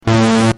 Free MP3 vintage Sequential circuits Pro-5 loops & sound effects 2